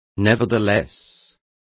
Complete with pronunciation of the translation of nevertheless.